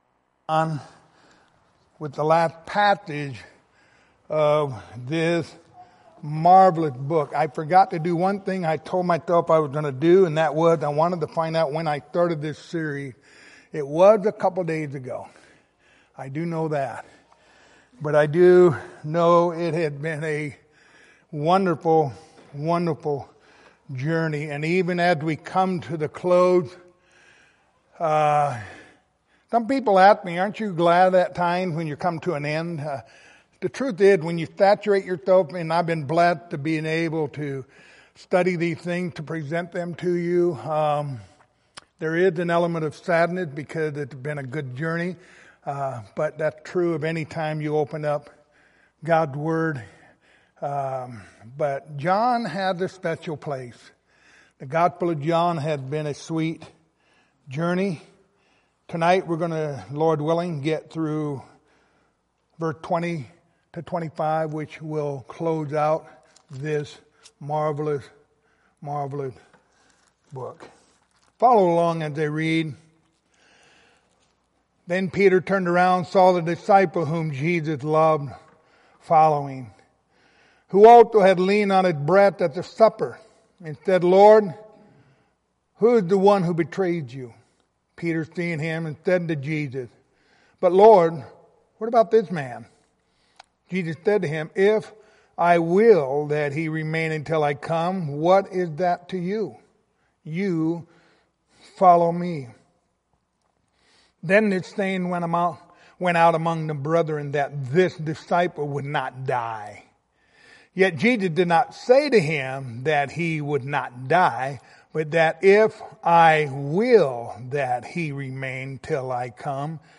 Passage: John 21:20-25 Service Type: Wednesday Evening